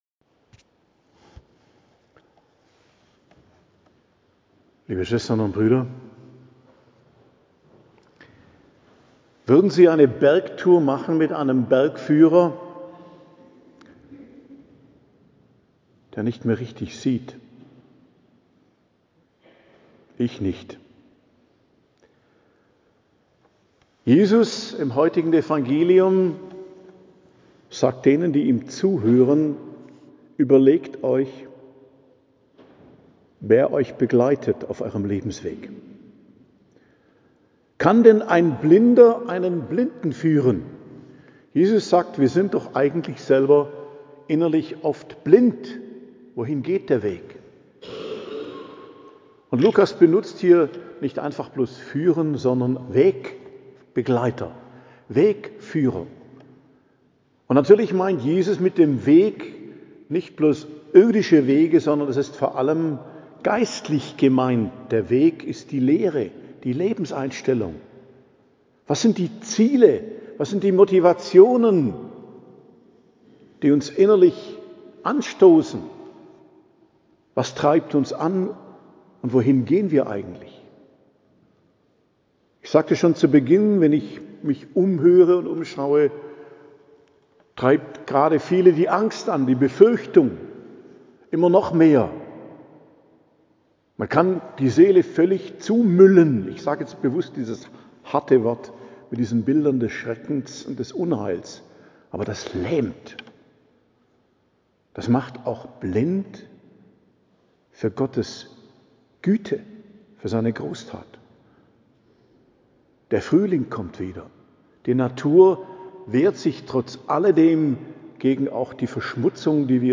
Predigt zum 8. Sonntag i.J. am 2.03.2025 ~ Geistliches Zentrum Kloster Heiligkreuztal Podcast